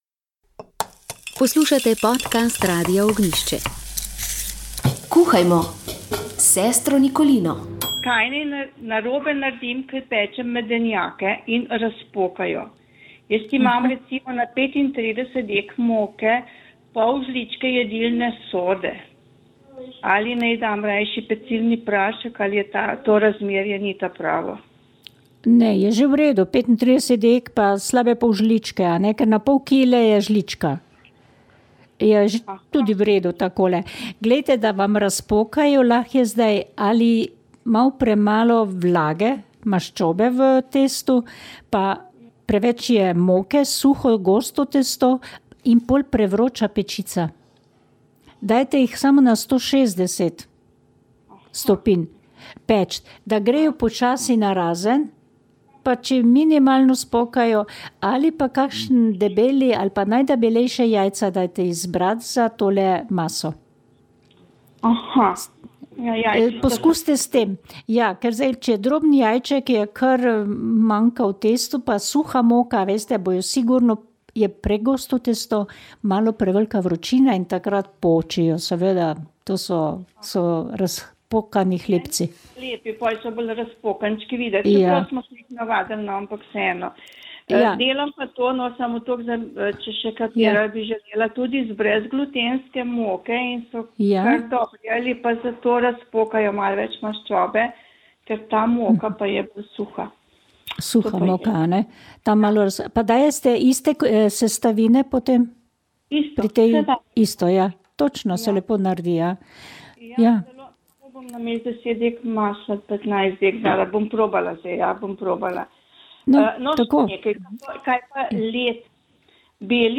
Radio Ognjišče knjiga Tessa Afshar Radijski roman VEČ ...